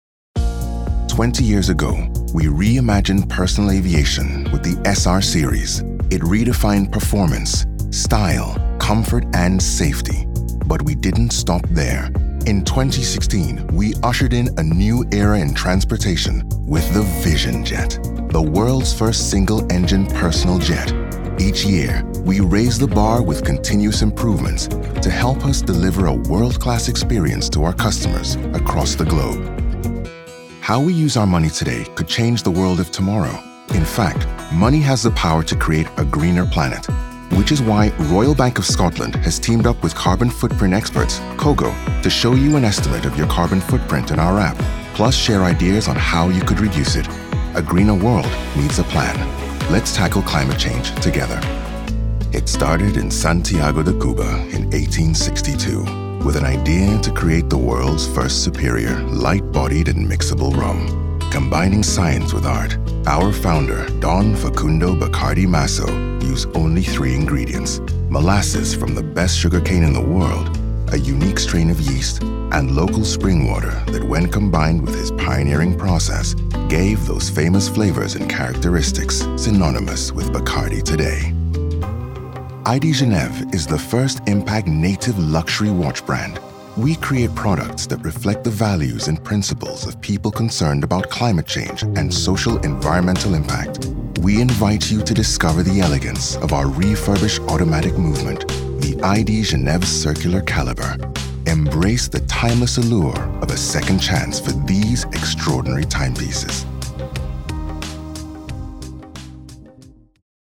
Profundo, Natural, Llamativo, Travieso, Versátil
Corporativo